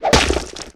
tentackle.ogg